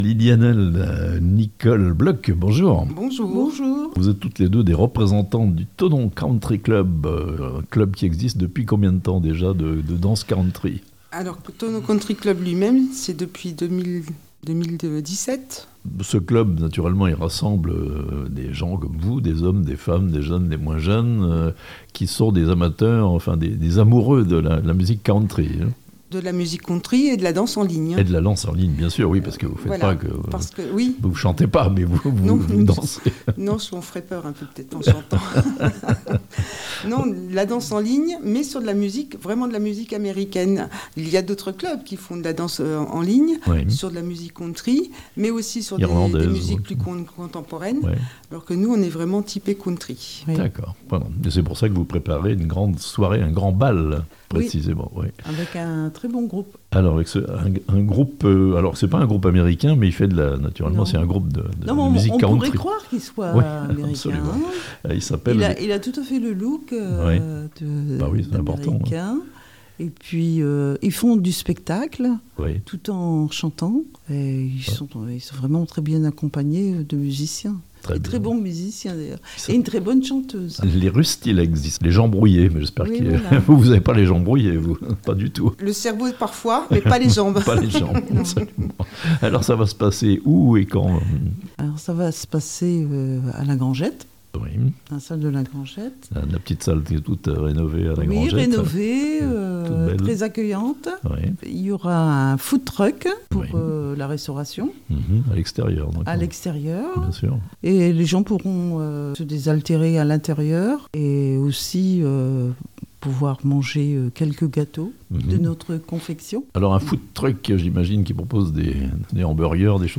Le Thonon Country Club organise, le samedi 10 février, un grand bal "Country" à la Maison des Associations de Thonon (interviews)